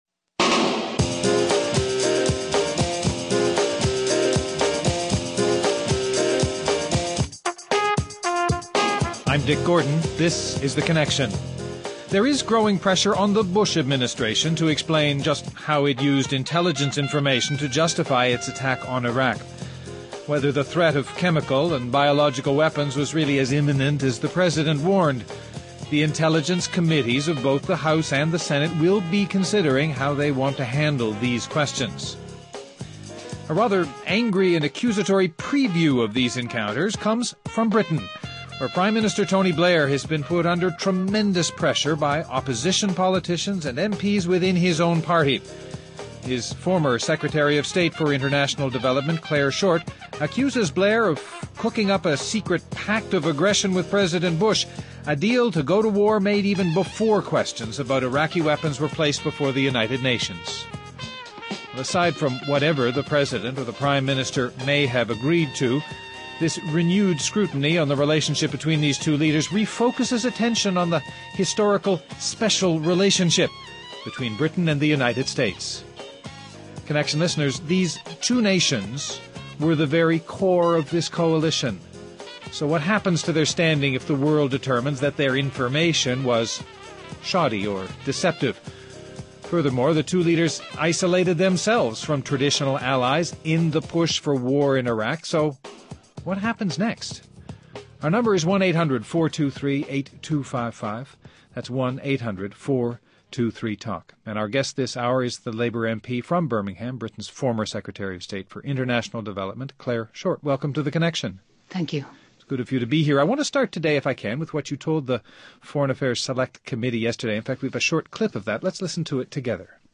The long and the short of this Anglo-American alliance Guests: Clare Short, Labour MP and former U.K. Secretary of International Development in Tony Blair’s cabinet